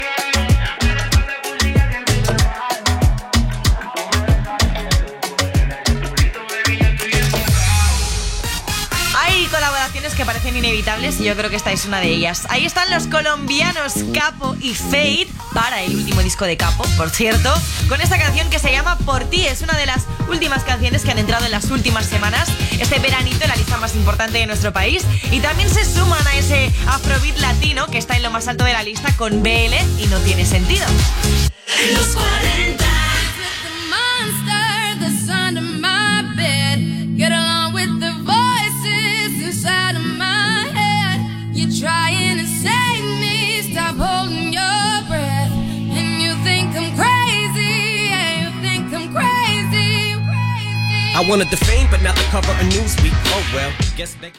Tema musical, presentació del següent tema, indicatiu i tema musical Gènere radiofònic Musical